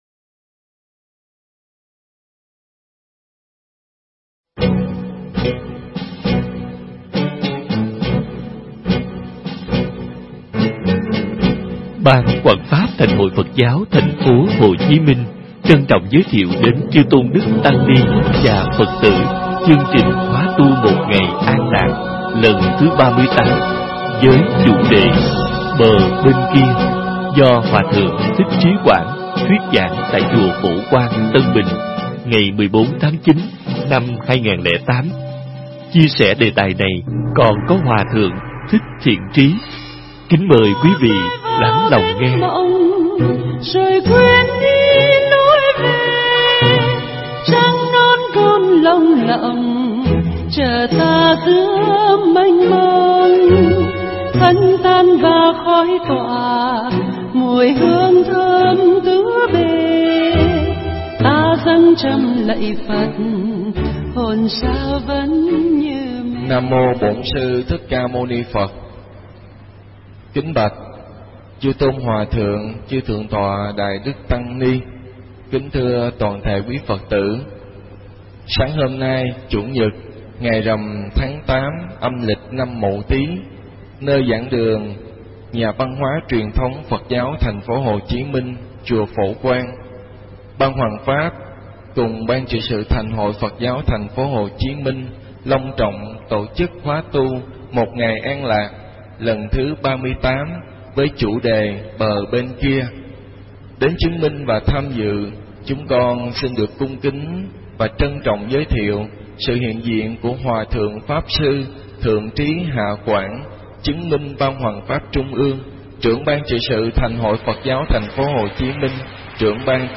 Bài Giảng mp3 Thuyết Pháp Bờ Bên Kia – Hòa Thượng Thích Trí Quảng giảng trong Khóa Tu Lần Thứ 38, ngày 14 tháng 9 năm 2008